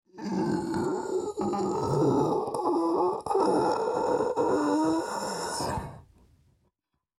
Звук последнего вздоха и гибели Кощея